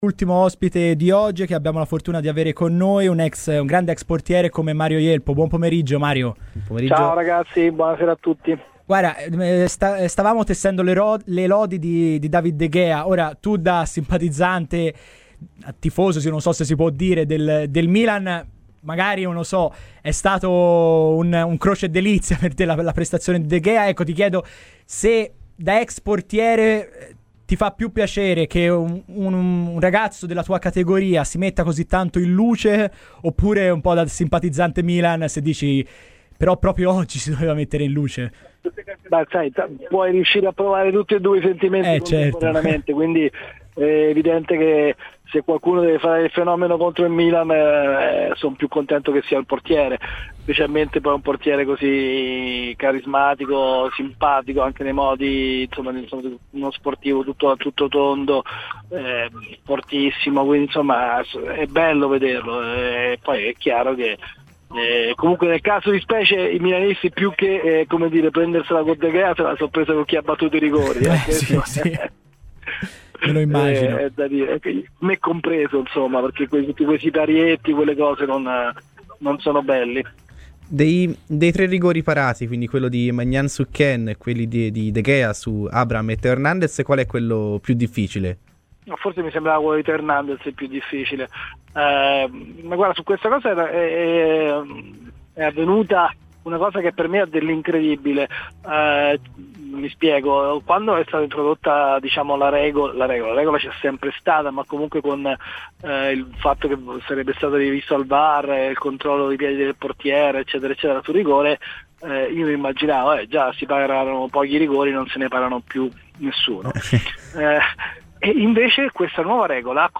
ASCOLTA DAL PODCAST L'INTERVISTA COMPLETA